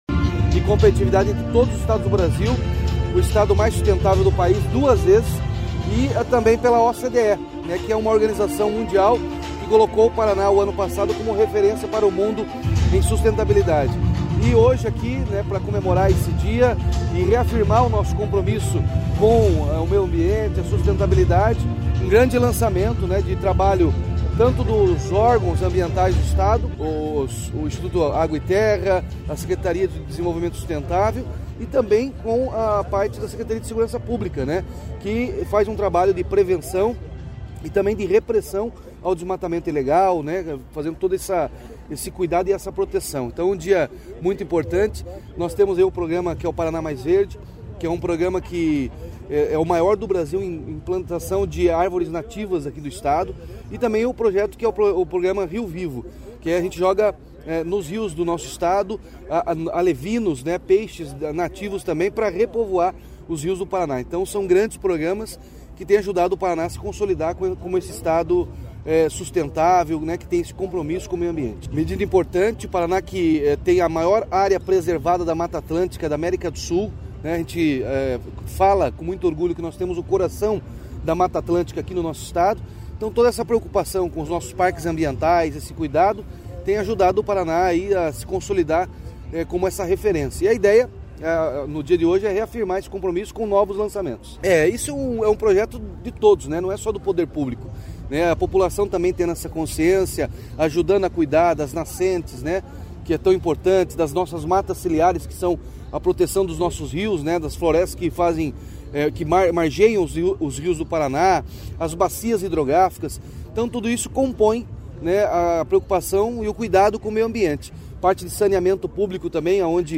Sonora do governador Ratinho Junior sobre os projetos lançados pelo IAT para conservação da fauna